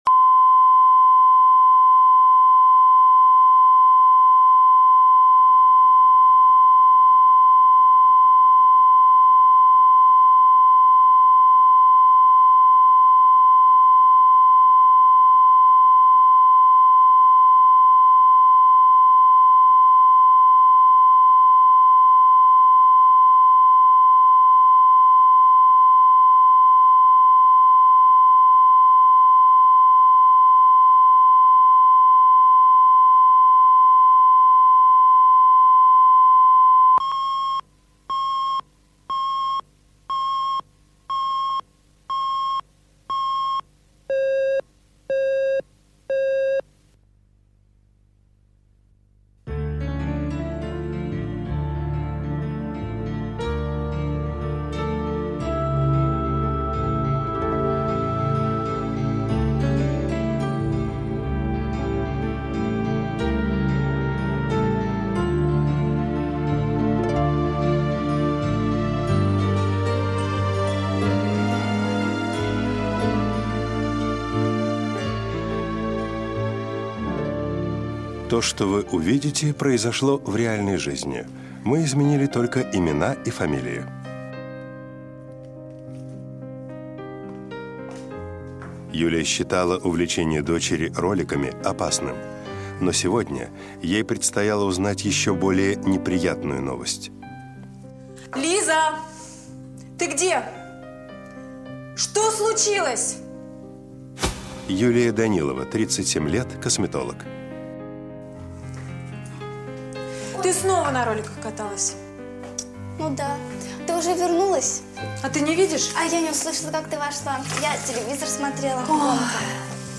Аудиокнига Последняя любовь | Библиотека аудиокниг
Прослушать и бесплатно скачать фрагмент аудиокниги